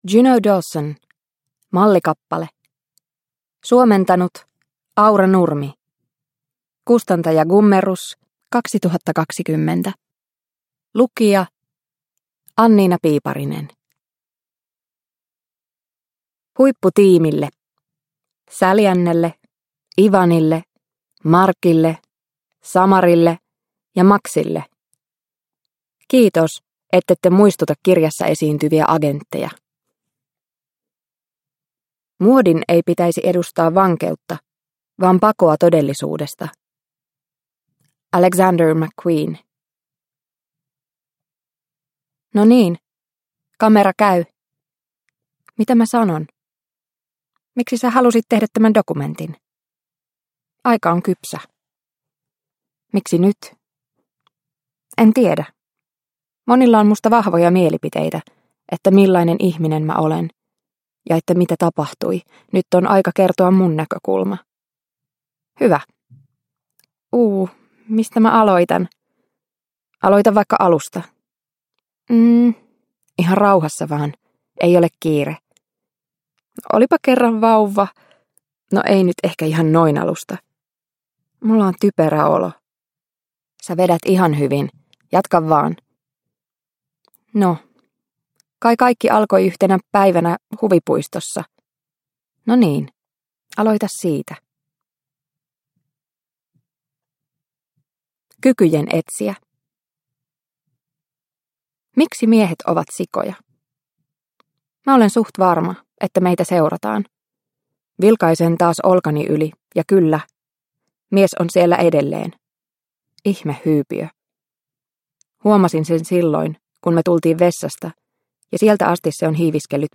Mallikappale – Ljudbok – Laddas ner